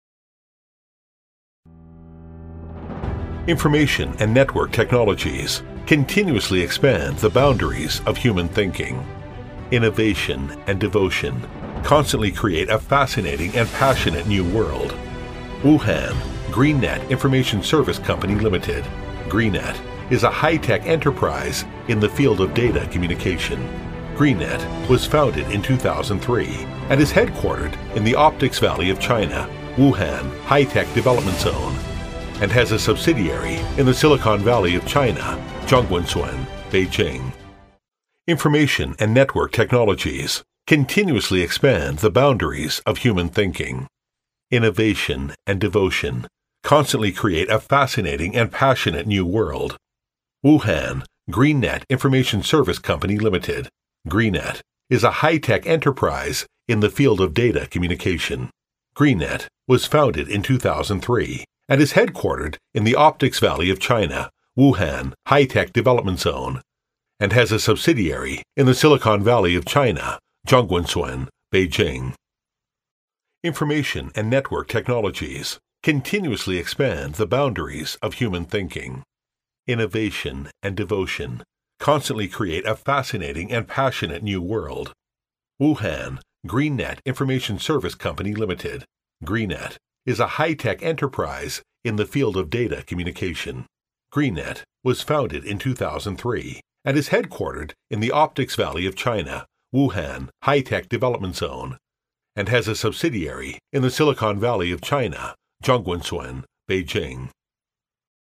• T110-1 美式英语 男声 企业宣传片 激情激昂|大气浑厚磁性|沉稳|低沉|娓娓道来